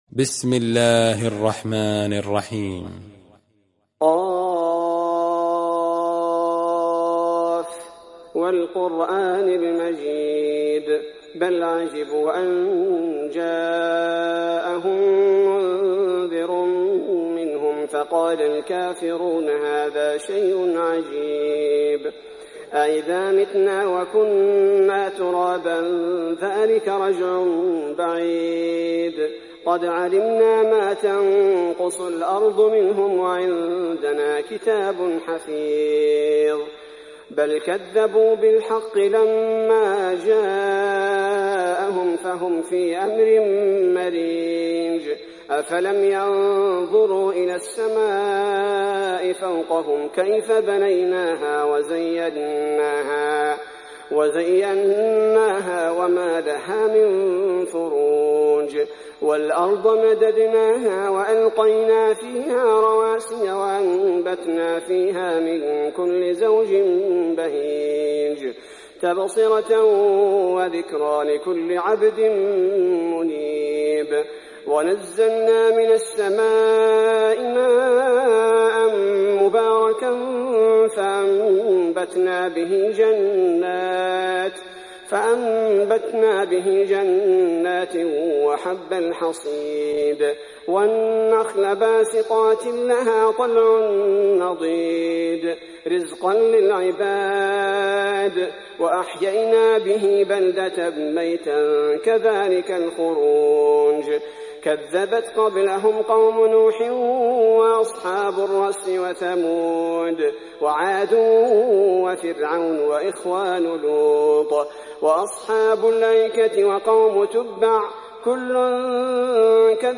دانلود سوره ق mp3 عبد الباري الثبيتي روایت حفص از عاصم, قرآن را دانلود کنید و گوش کن mp3 ، لینک مستقیم کامل